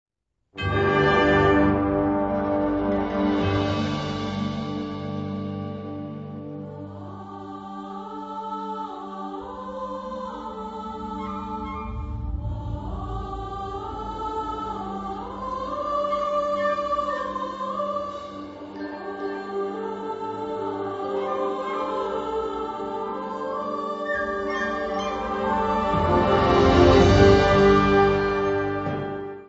Catégorie Harmonie/Fanfare/Brass-band
Sous-catégorie Musique à vent contemporaine (1945-présent)
Choir (players sing)